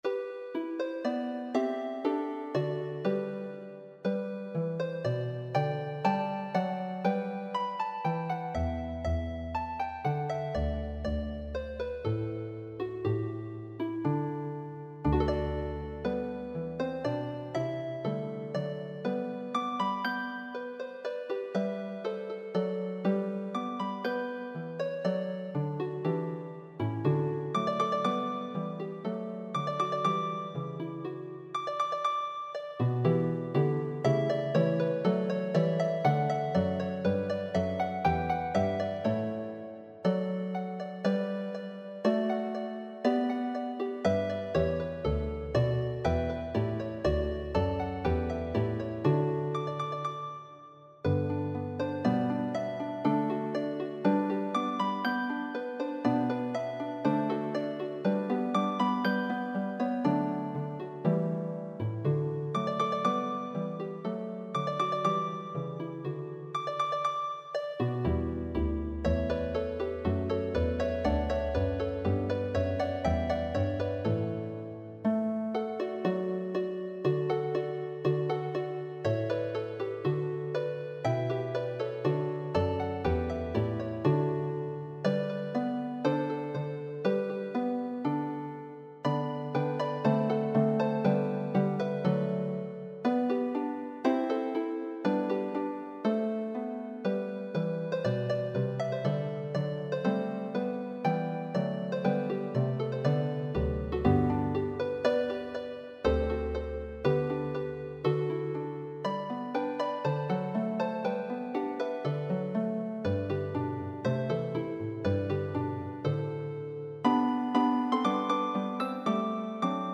for two lever or pedal harps
peppy, popular piece